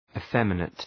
Προφορά
{ı’femənıt}